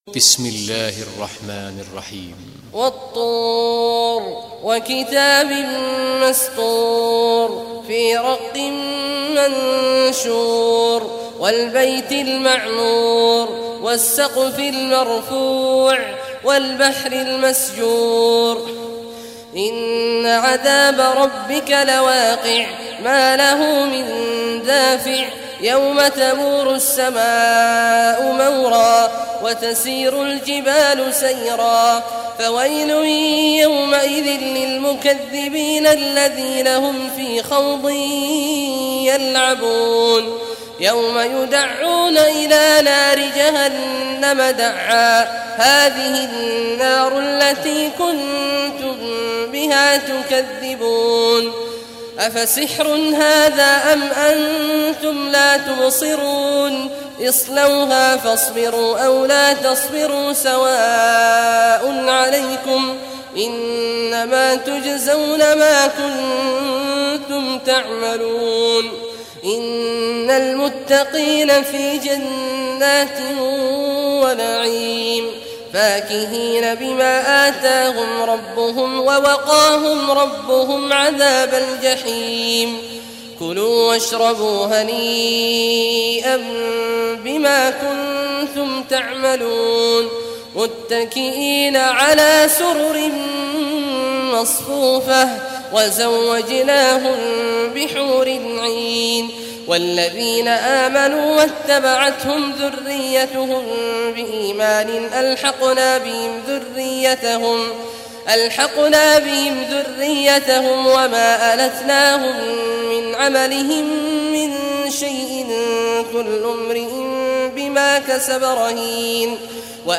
Surah Tur Recitation by Sheikh Abdullah Al Juhany
Surah Tur, listen or play online mp3 tilawat / recitation in Arabic in the beautiful voice of Sheikh Abdullah Awad al Juhany.